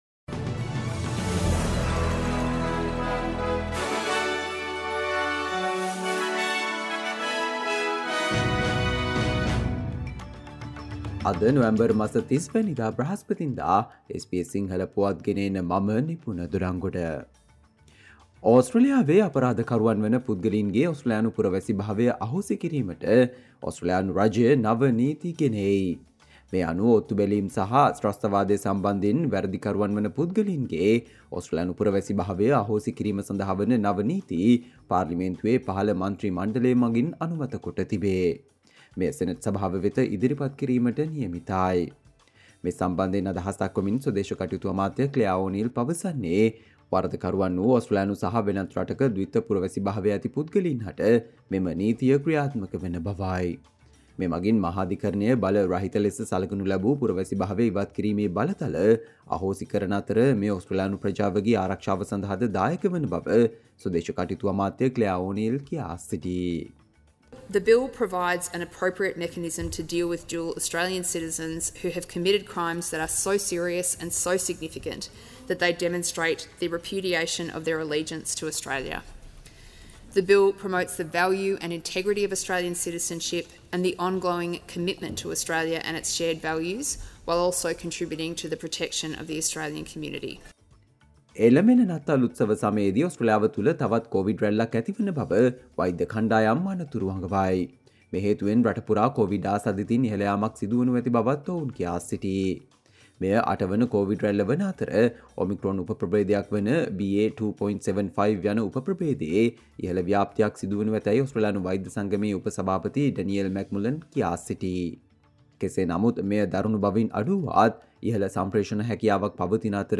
Australia news in Sinhala, foreign and sports news in brief - listen, Thursday 30 November 2023 SBS Sinhala Radio News Flash